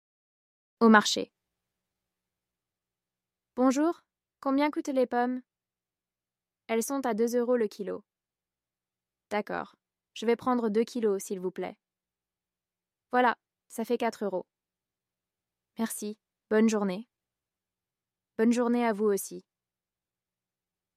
Dialogue FLE – Au marché (niveau A2)
Le client – the customer
Le vendeur – the seller